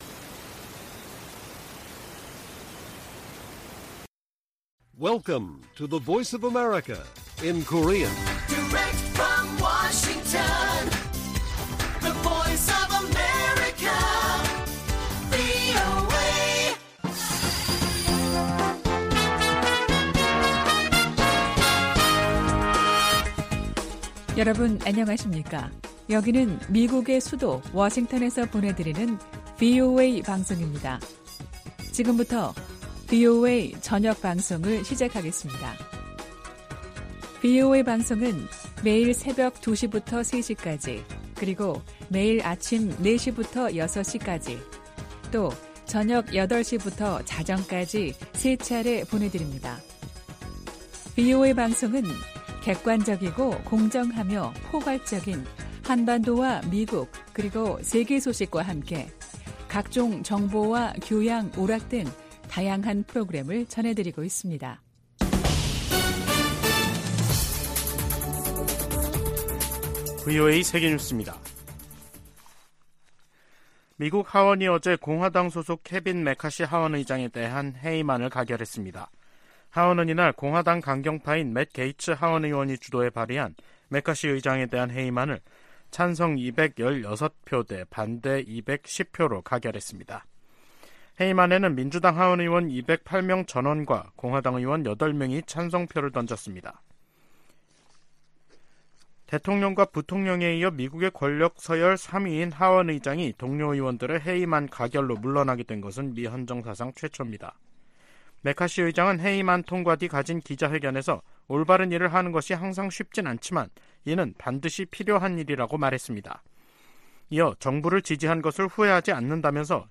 VOA 한국어 간판 뉴스 프로그램 '뉴스 투데이', 2023년 10월 4일 1부 방송입니다. 북한 국방성은 미국의 '2023 대량살상무기(WMD) 대응 전략'을 "또 하나의 엄중한 군사정치적 도발"이라고 규정했습니다. 미 국무부는 핵 보유가 주권 행사라는 최선희 북한 외무상의 주장에, 북한은 절대 핵보유국으로 인정받지 못할 것이라고 강조했습니다. 미 국방부는 북한과 러시아 간 추가 무기 거래가 이뤄져도 놀랍지 않다는 입장을 밝혔습니다.